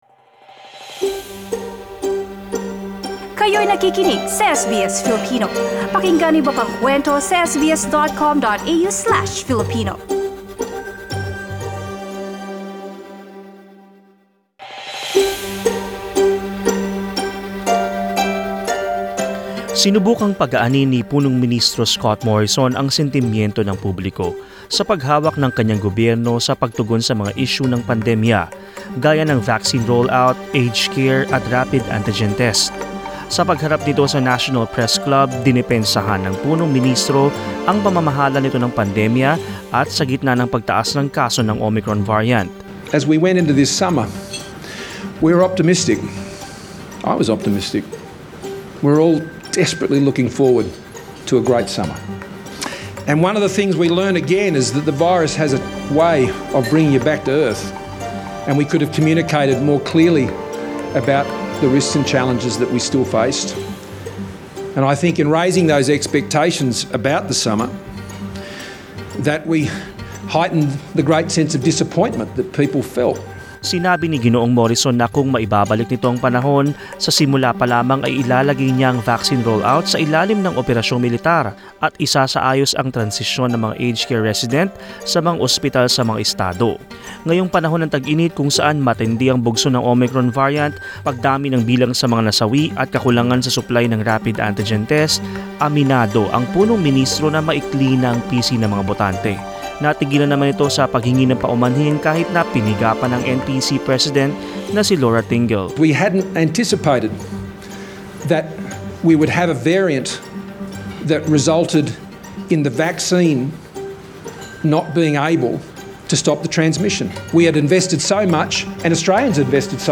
Prime Minister Scott Morrison at the National Press Club in Canberra, Tuesday, February 1, 2022.